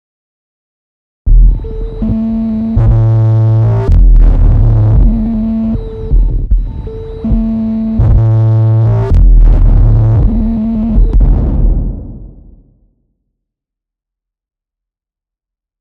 При разных значениях этого параметра можно получать довольно разное звучание на одних и тех же настройках: